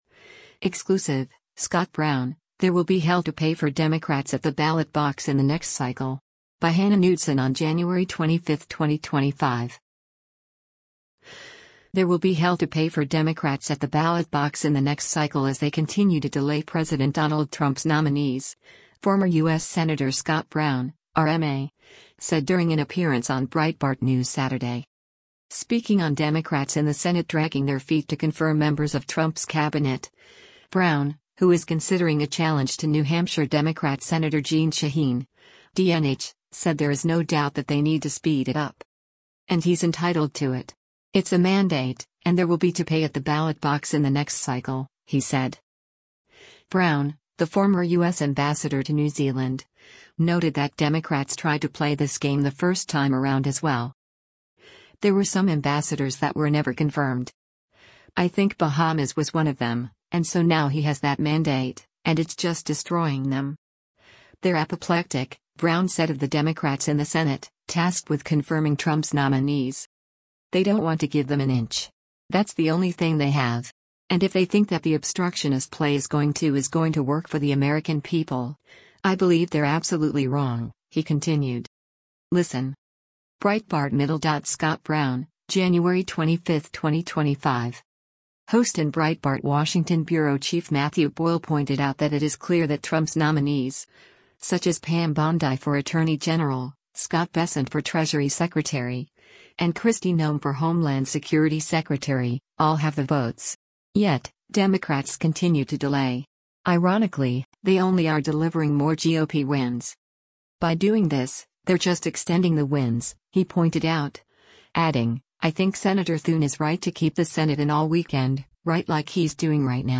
“There will be hell to pay” for Democrats at the ballot box in the next cycle as they continue to delay President Donald Trump’s nominees, former U.S. Senator Scott Brown (R-MA) said during an appearance on Breitbart News Saturday.